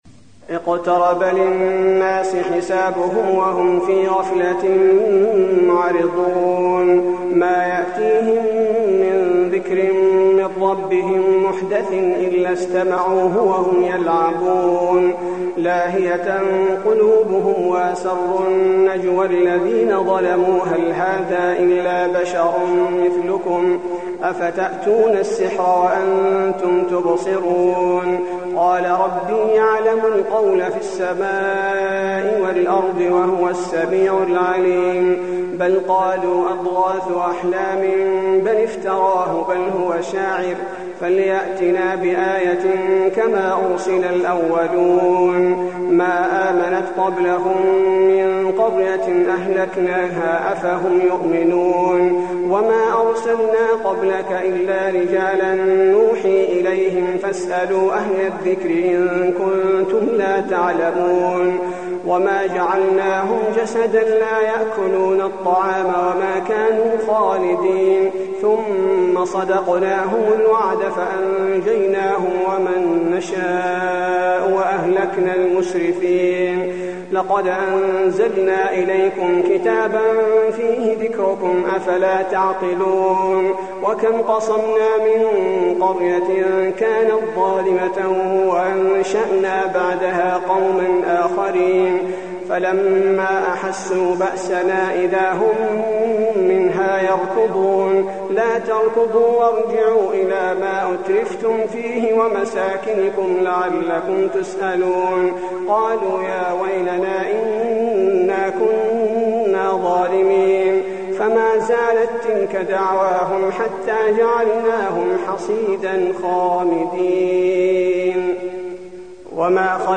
المكان: المسجد النبوي الأنبياء